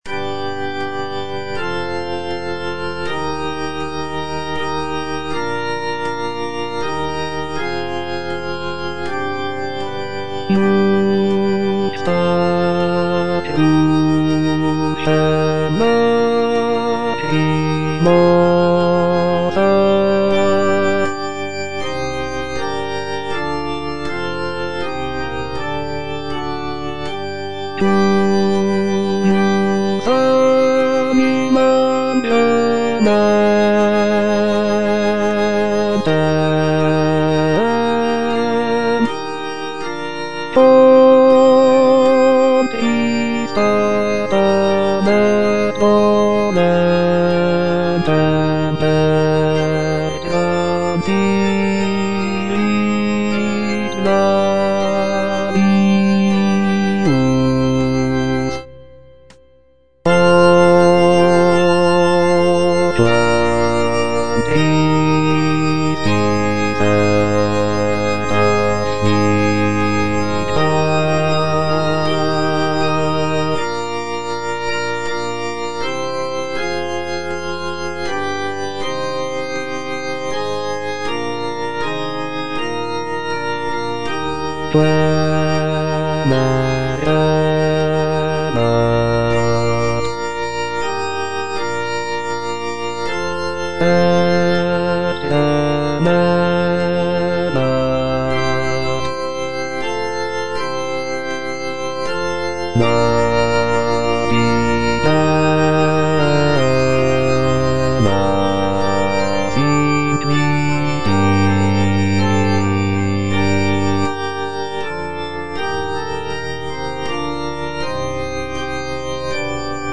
(bass II) (Voice with metronome) Ads stop
sacred choral work